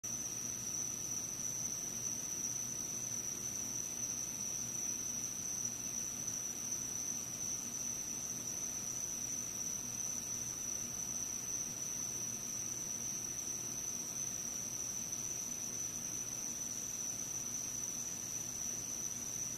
Светлячки в ночном лесу жук светляк